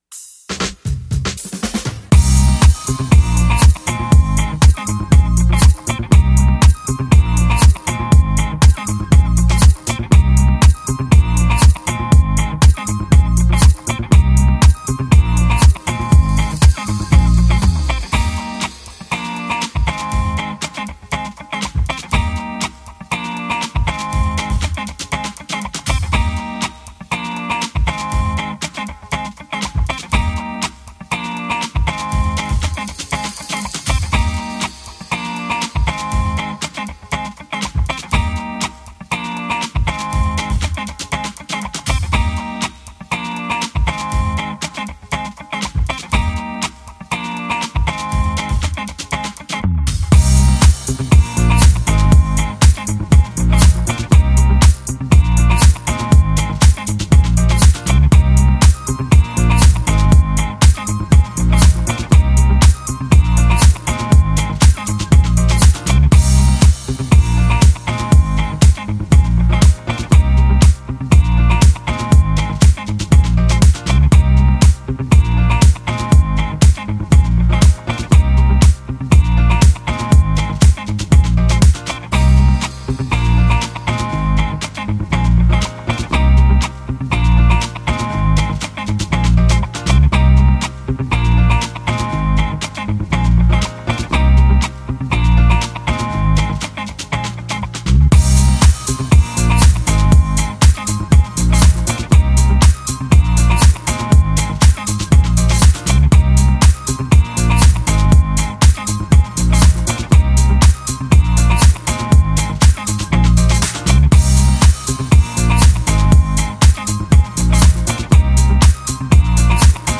Žánr: Electro/Dance
Dalsi hit s prvkami house, zenskym vokalom a saxafonom.